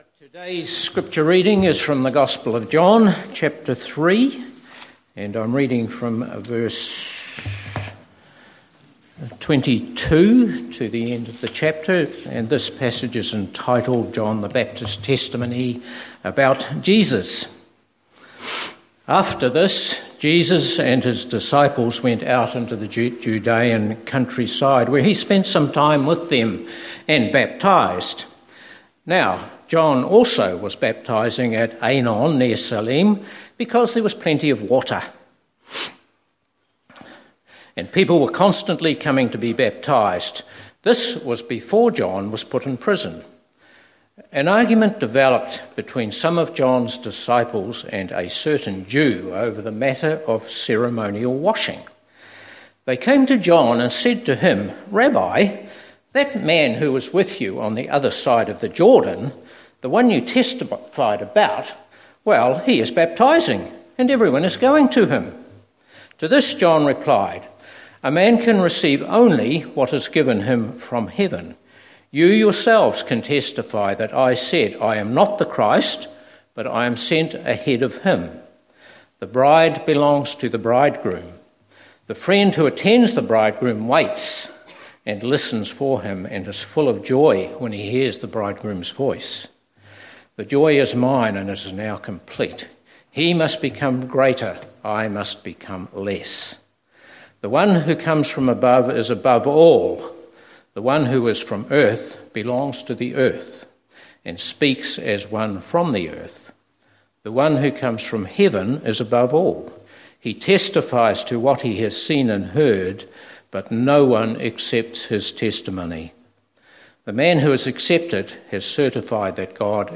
Reading John 3:22-36